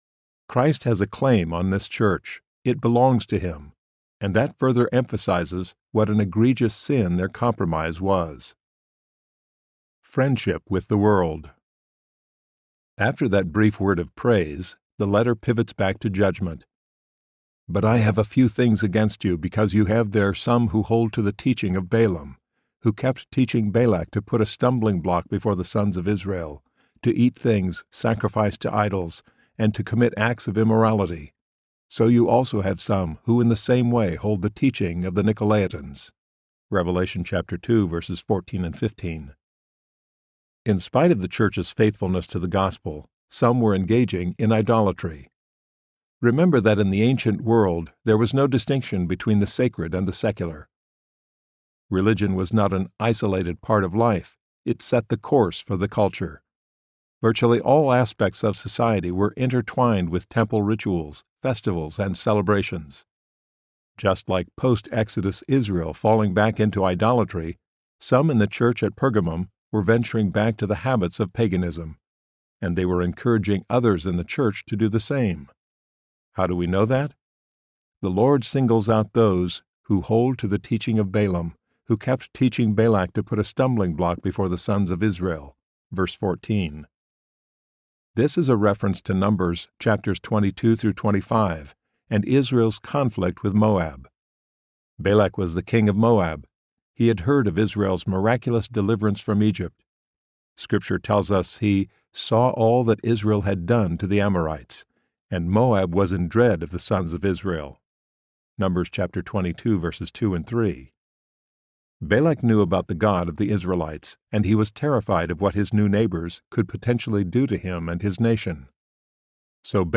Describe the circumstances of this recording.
6.33 Hrs. – Unabridged